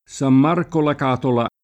Catola [ k # tola ] top. f. (Puglia) — torrente